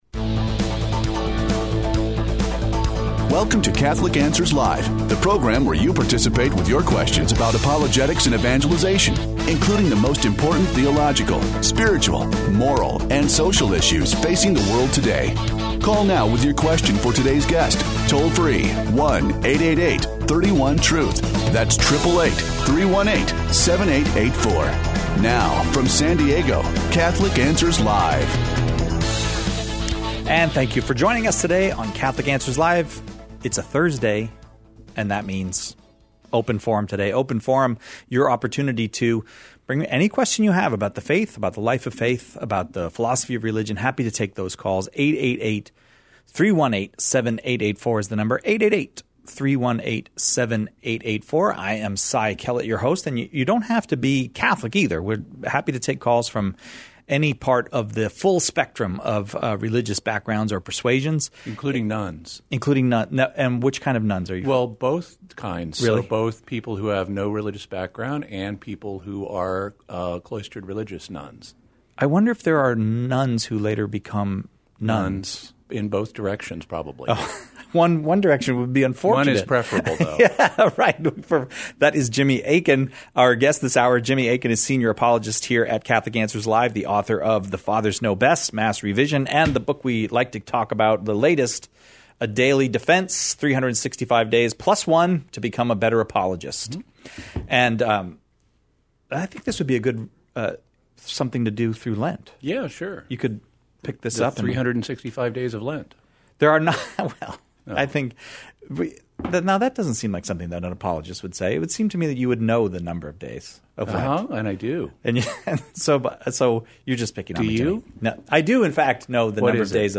The callers choose the topics during Open Forum, peppering our guests questions on every aspect of Catholic life and faith, the moral life, and even philosophic...